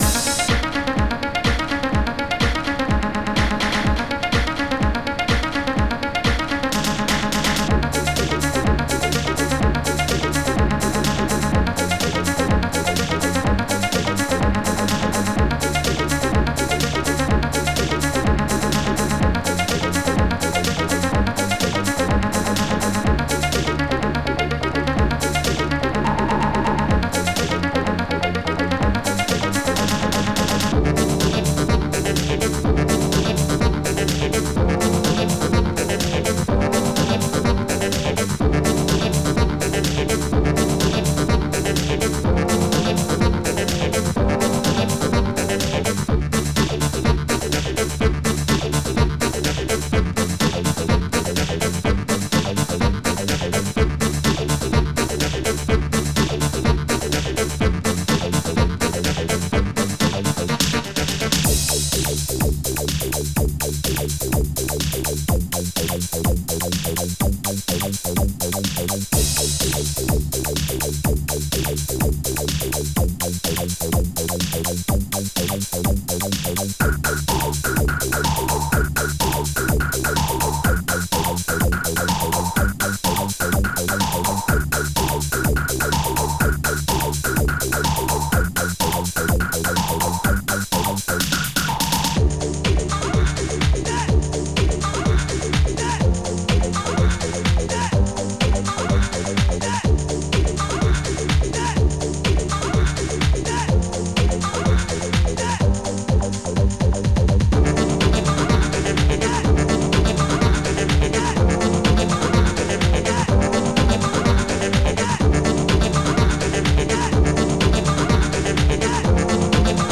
Protracker and family
st-19:drum12
st-26:snare52c
st-07:claps4-snare
st-25:hihat-col.14
st-19:house-cymbal8